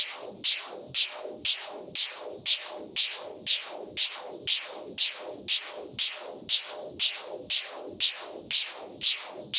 STK_MovingNoiseF-100_02.wav